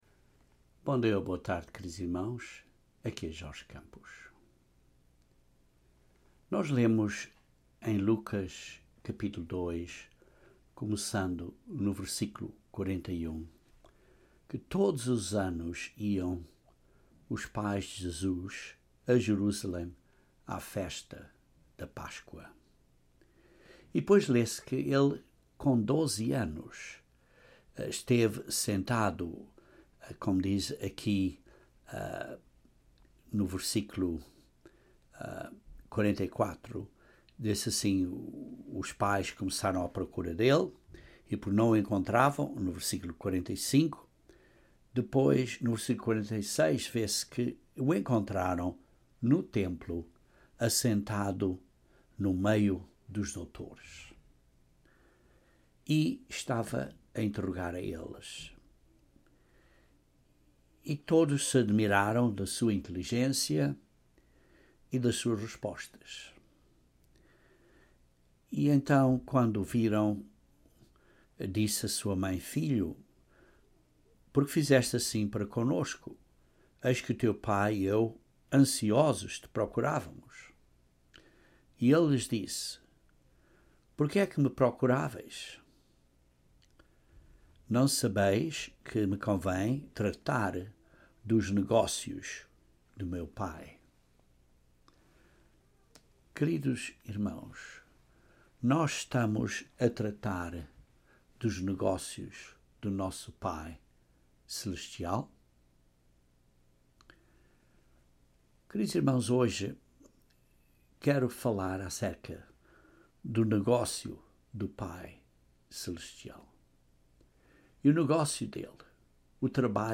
Este sermão examina sete passos importantes da Obra do Pai.